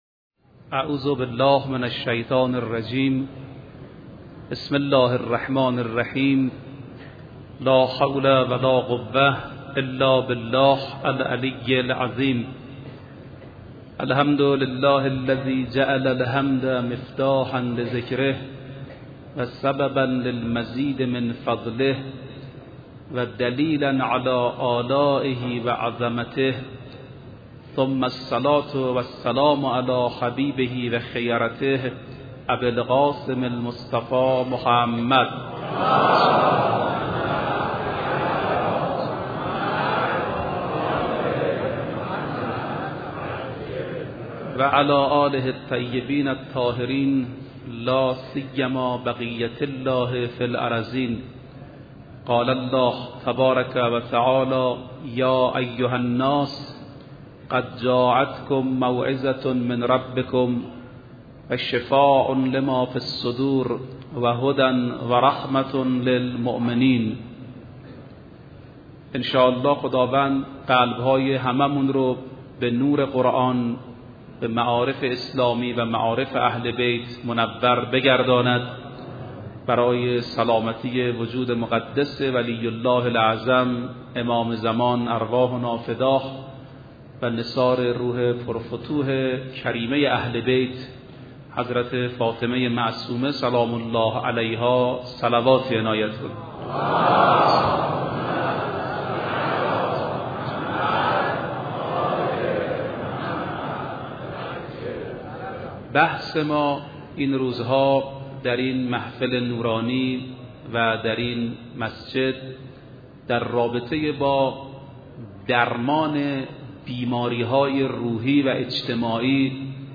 سخنرانی استاد رفیعی درمان با قرآن کریم